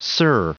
Prononciation du mot sir en anglais (fichier audio)
Prononciation du mot : sir